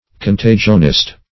Search Result for " contagionist" : The Collaborative International Dictionary of English v.0.48: Contagionist \Con*ta"gion*ist\, n. One who believes in the contagious character of certain diseases, as of yellow fever.